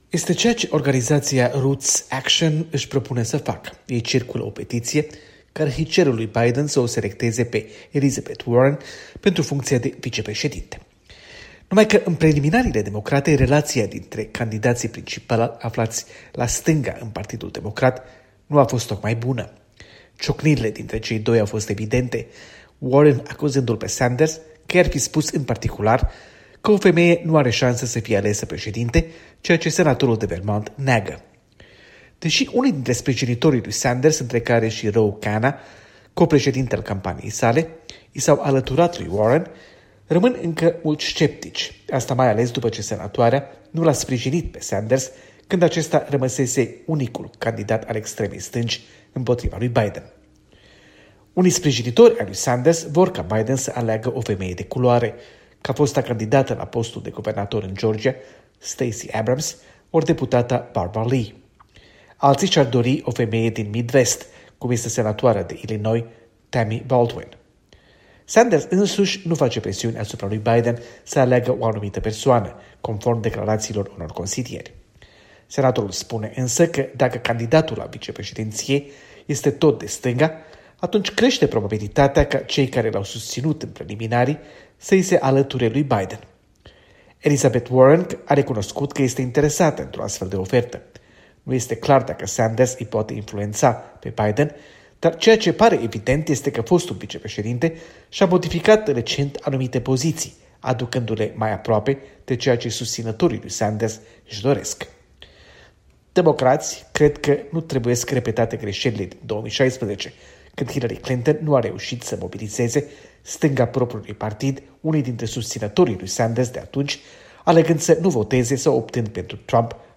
Corespondență de la Washington: Elisabeth Warren